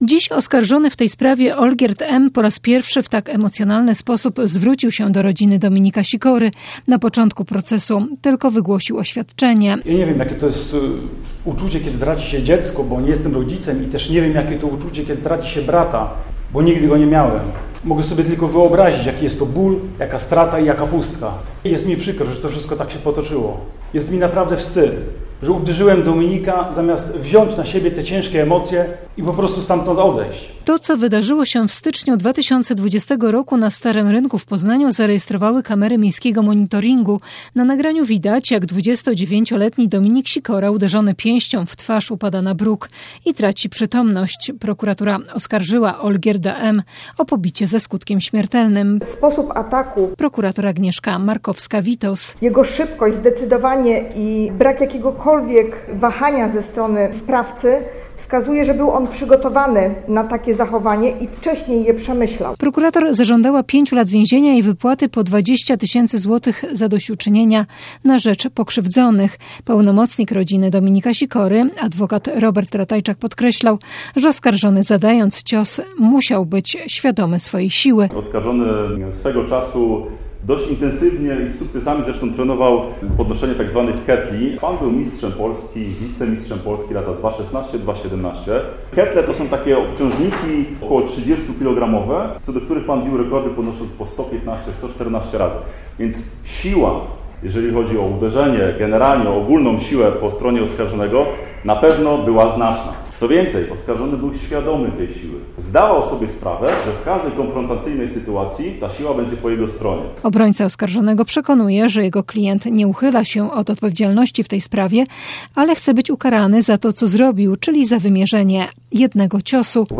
Oskarżony w emocjonalnym wystąpieniu zwrócił się dziś do rodziny zapaśnika.
- wyjaśnia oskarżony.